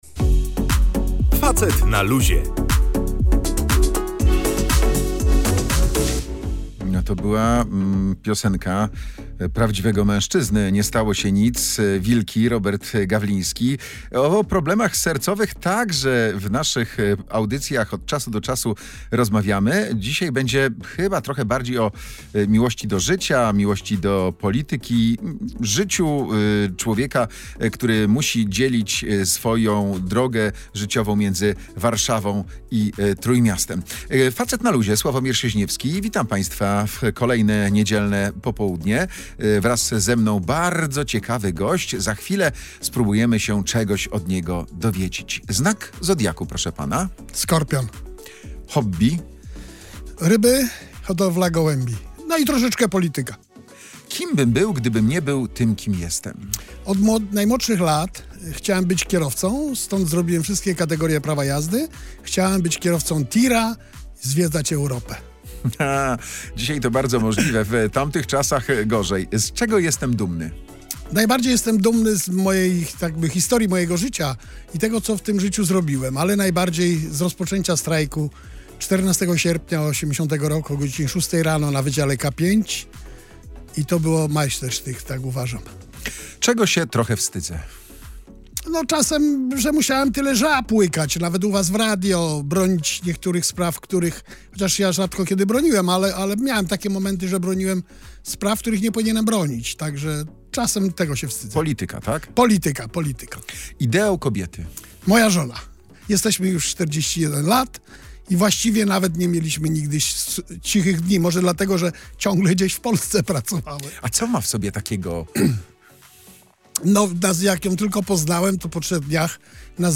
Jerzy Borowczak w audycji „Facet na luzie” mówił o tym, jak wyglądał początek strajku, o chwilach triumfu i zwątpienia. Sporo czasu poświęcił Lechowi Wałęsie, z którym współpracował i przyjaźni się do dziś.